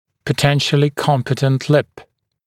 [pə’tenʃəlɪ ‘kɔmpɪtənt lɪp][пэ’тэншэли ‘компитэнт лип]потенциально смыкаемая губа